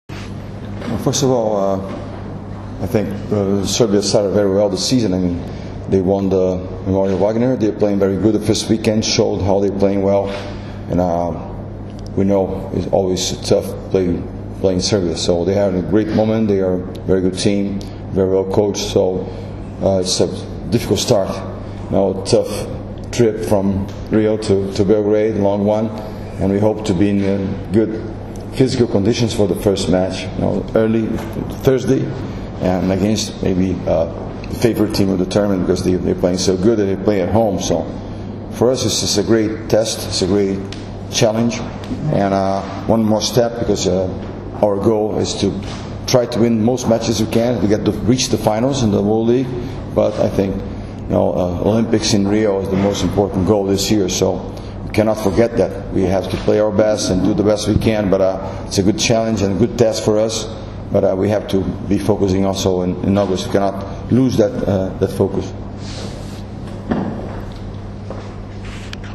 U beogradskom hotelu “M” danas je održana konferencija za novinare povodom turnira F grupe XXVII Svetske lige 2016, koji će se odigrati u dvorani “Aleksandar Nikolić” u Beogradu od 23. – 25. juna.
IZJAVA BERNARDA REZENDEA